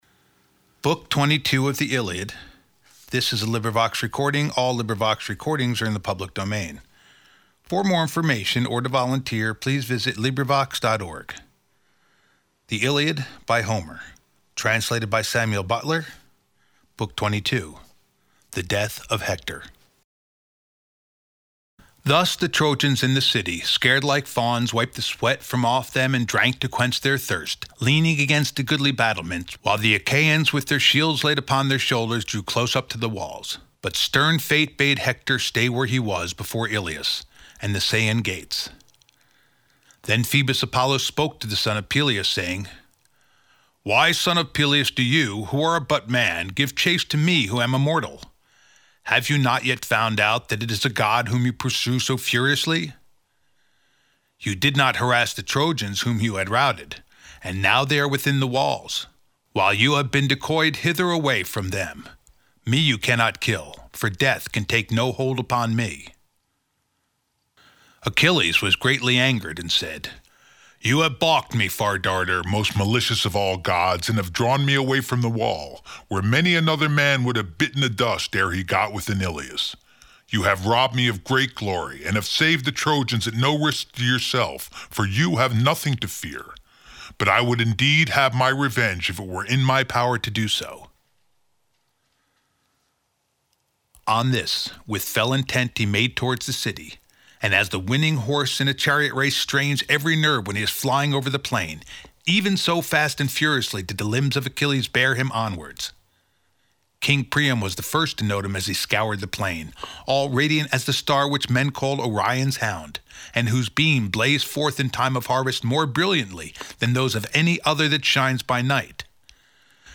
LibriVox recording by volunteers. The death of Hector.